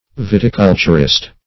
Search Result for " viticulturist" : Wordnet 3.0 NOUN (1) 1. a cultivator of grape vine ; The Collaborative International Dictionary of English v.0.48: Viticulturist \Vit`i*cul"tur*ist\, n. One engaged in viticulture.
viticulturist.mp3